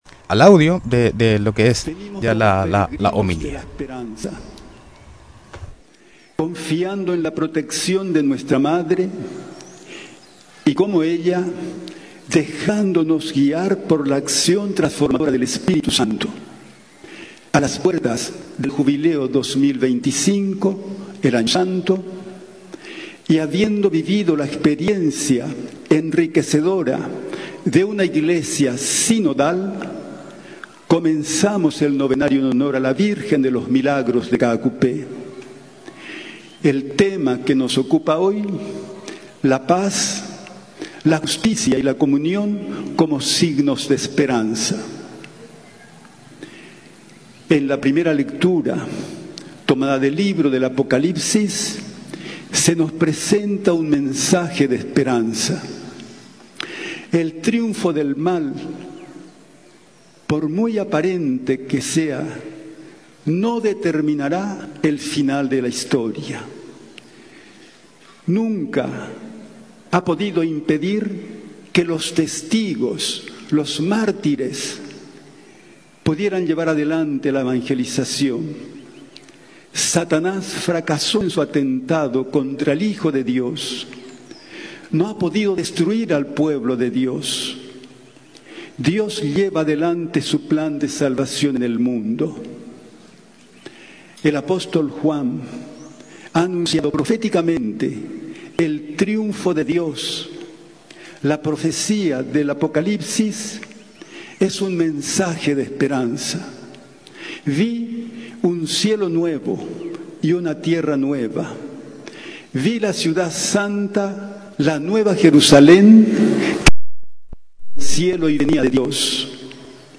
La paz, justicia y comunión como signo de esperanza, fue el mensaje presentado por el obispo de San Lorenzo, monseñor Joaquín Robledo, en el segundo día del novenario a la Virgen de Caacupé.